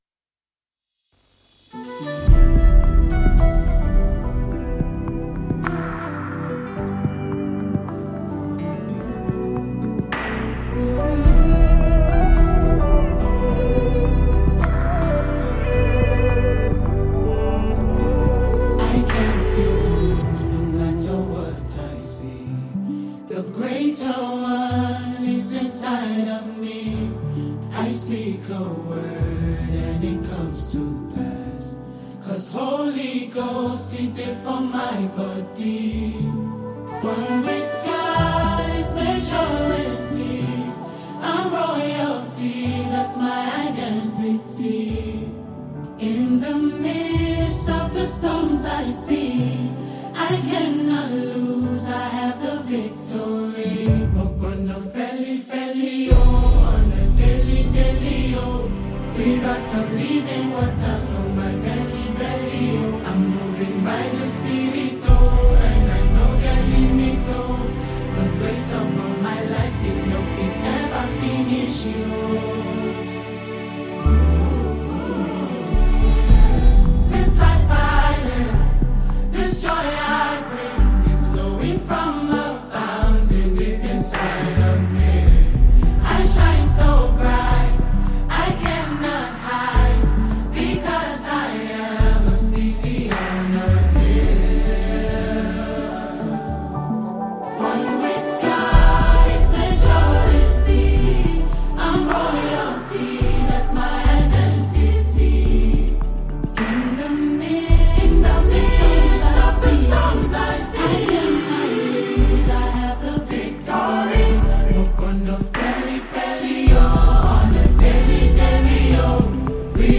An exceptionally talented Nigerian gospel musician